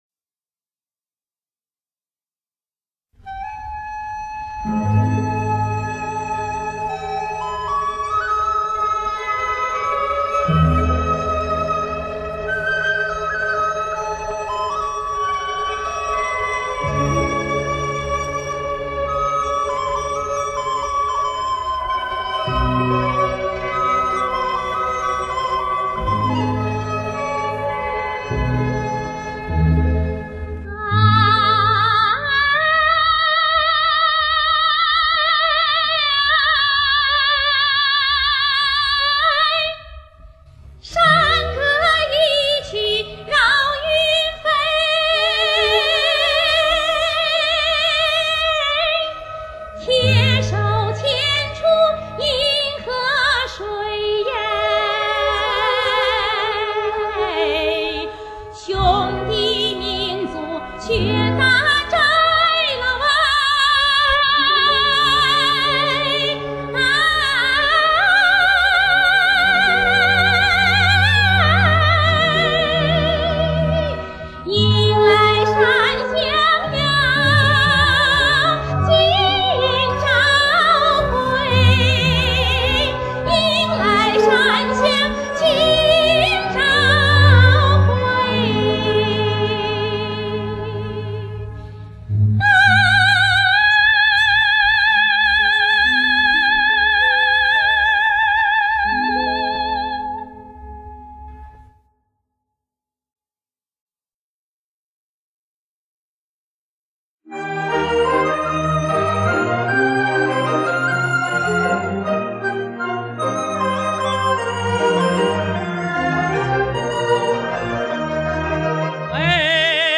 曲风欢快抒情